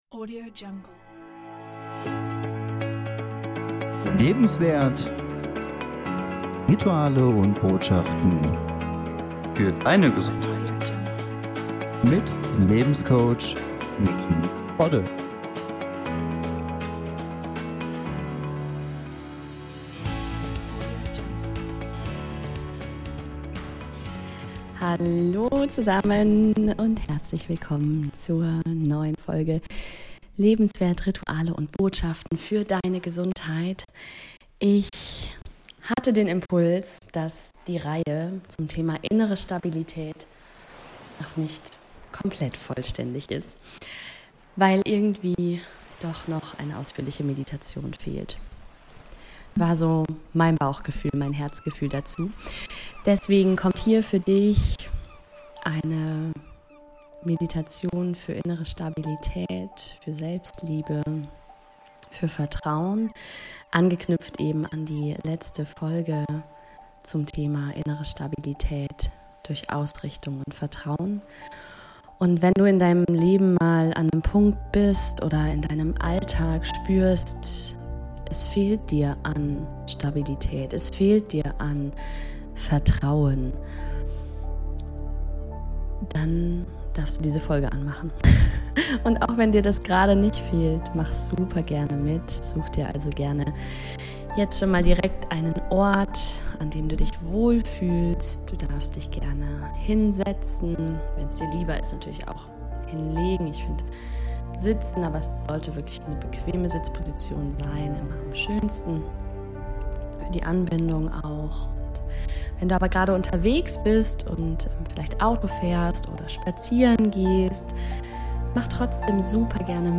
#079: Geführte Meditation für innere Stabilität ~ LEBEnswert Podcast
Du bist wundervoll & einzigartig. p.s. leider habe ich erst nach der Aufnahme einen technischen Fehler entdeckt, wodurch die Audioqualität nicht bei 100% ist.